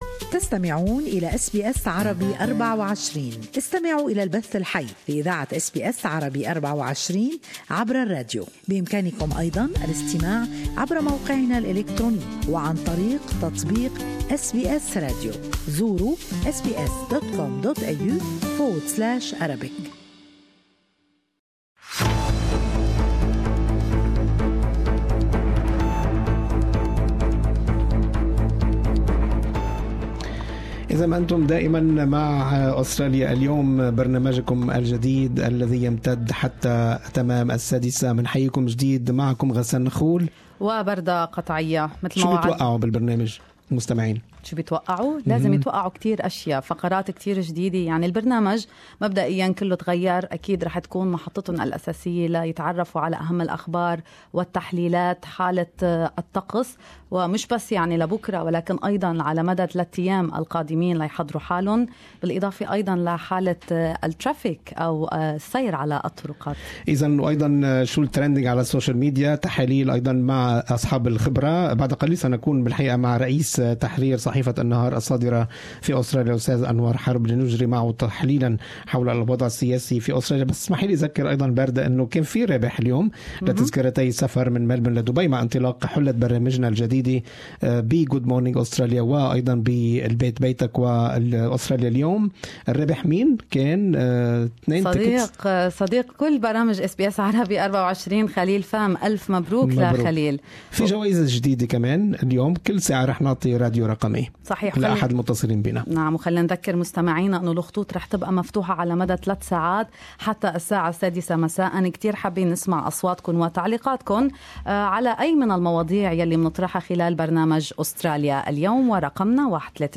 Would the Federal Parliament convene on time to deal with the pressing issues at hand? More in this interview